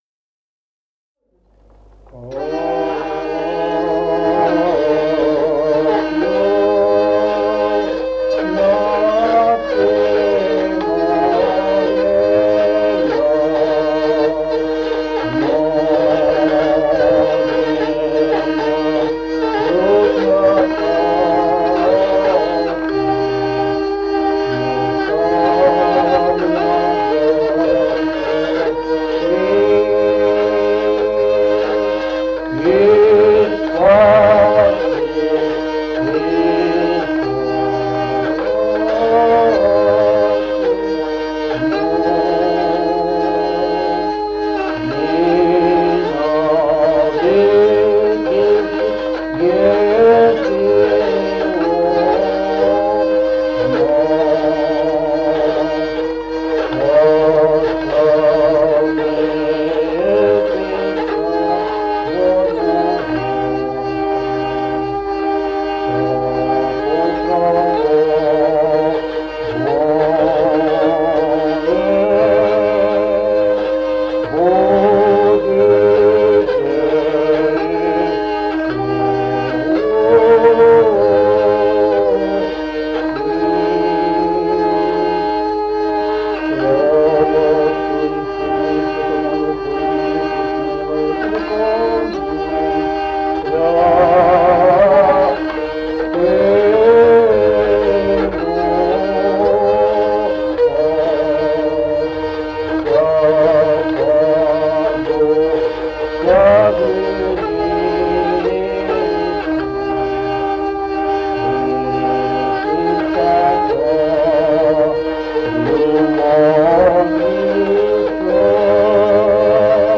Музыкальный фольклор села Мишковка «От юности моея», первый антифон 4-го гласа.